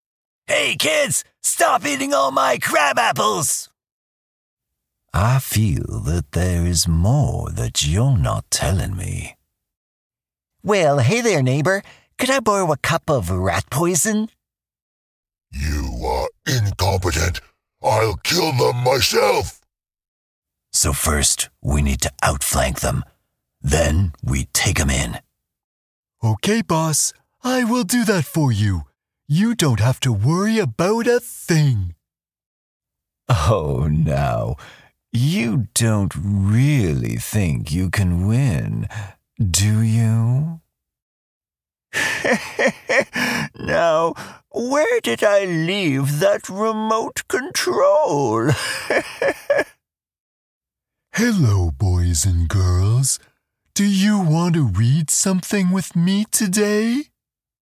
Animation demo
Voice acting including animation, feature films, video games and shorts
Known for my warm, resonant baritone, I provide clear, trusted narration for commercials, web videos, eLearning, corporate content, explainers, IVR, animated characters, video games, and more.